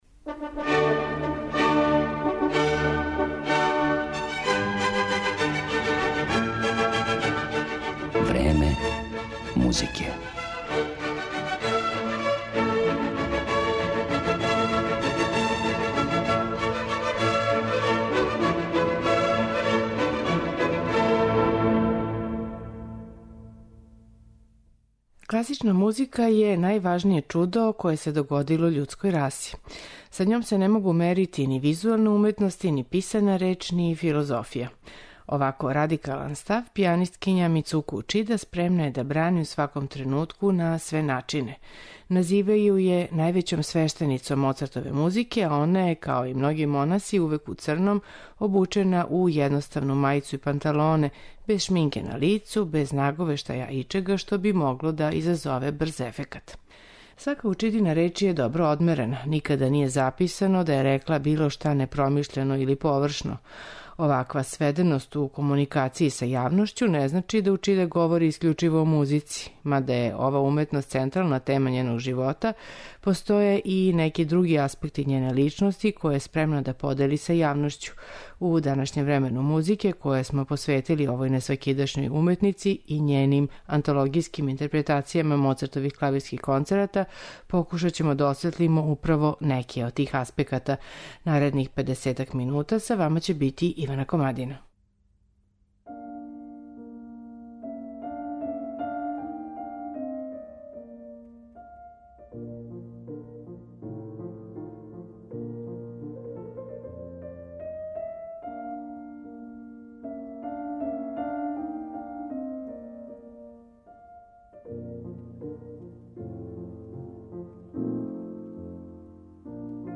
пијанисткињи